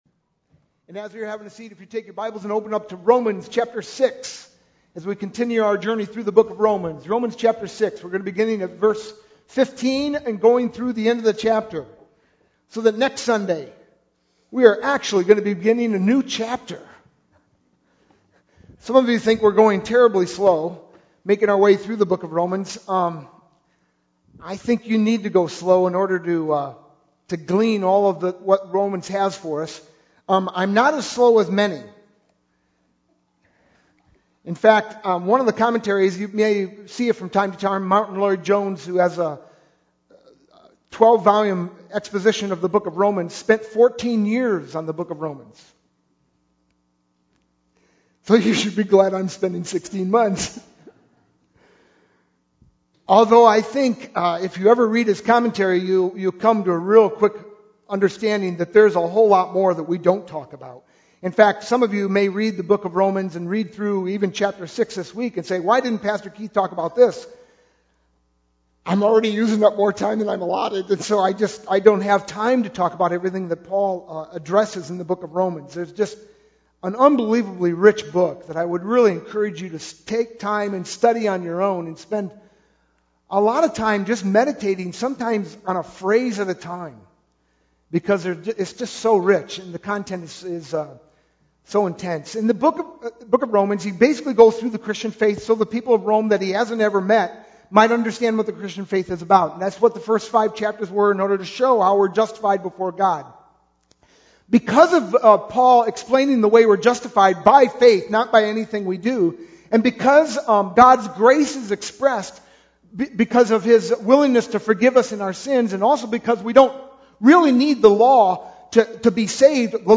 sermon-5-22-11.mp3